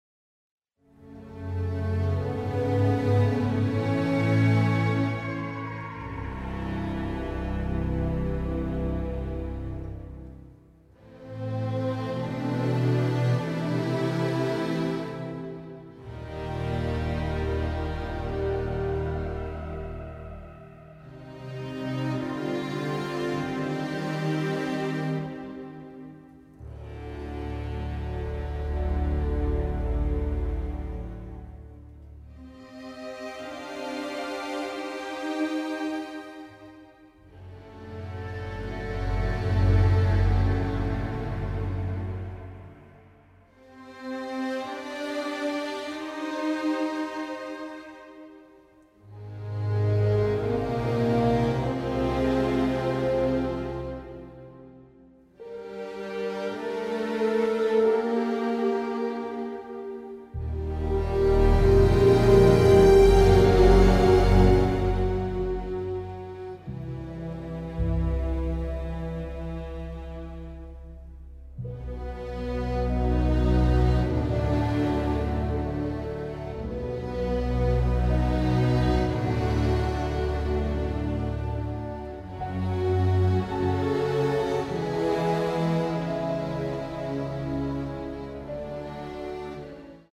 original motion picture score to the all-new